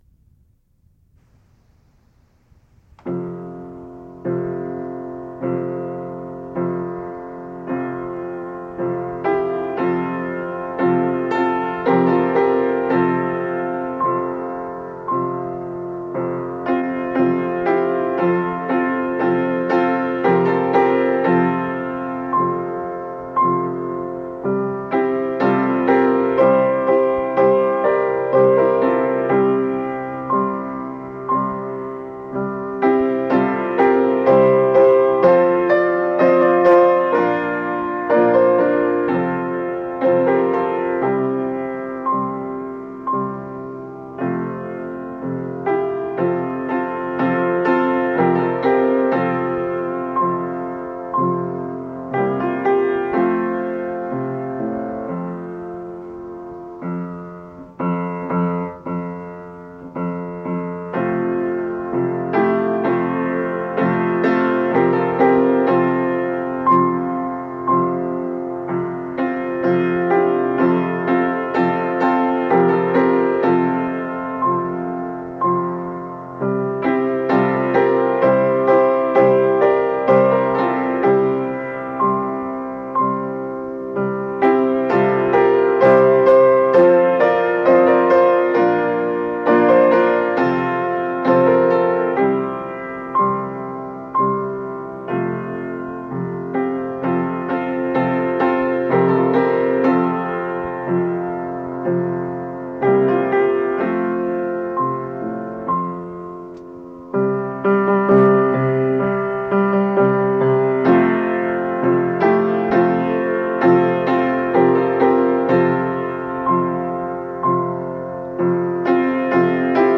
Here are some of my favorite piano arrangements